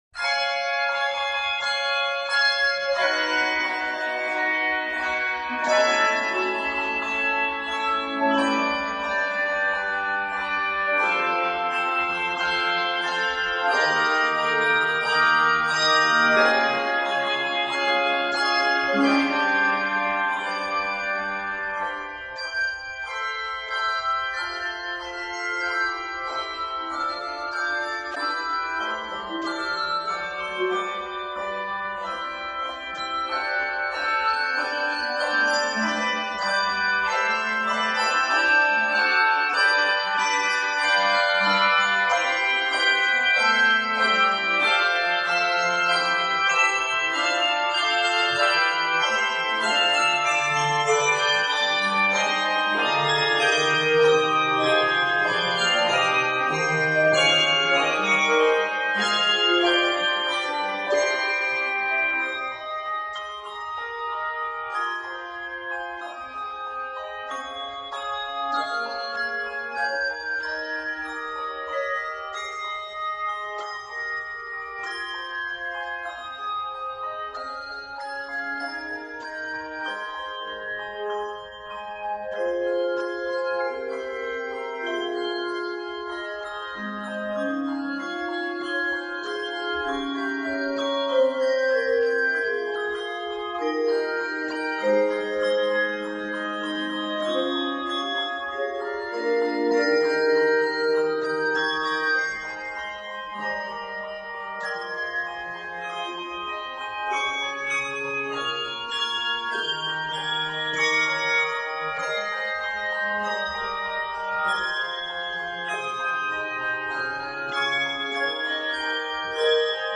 is a bold and stirring original composition
Octaves: 3-6 Level